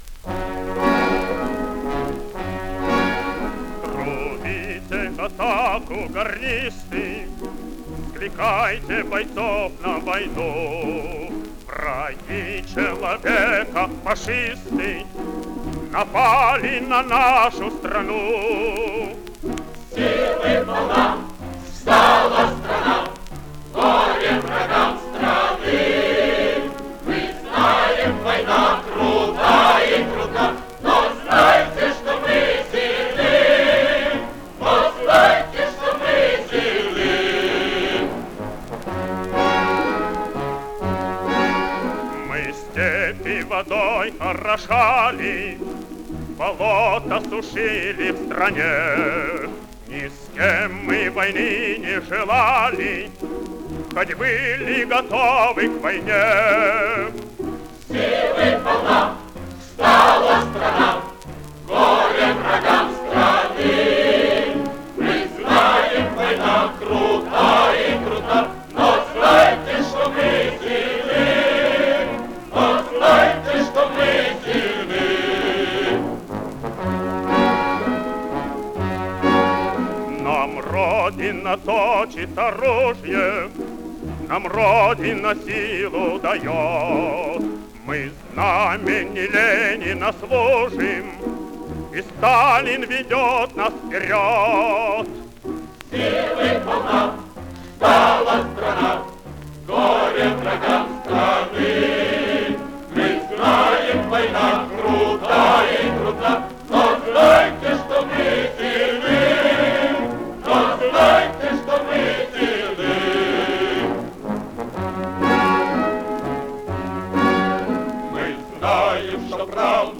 Характерная песня начала Великой Отечественной